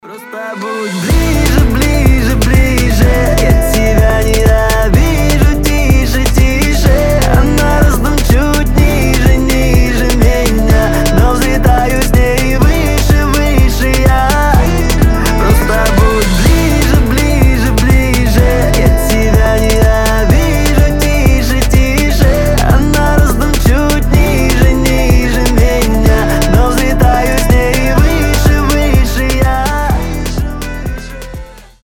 • Качество: 320, Stereo
мужской голос
ритмичные
заводные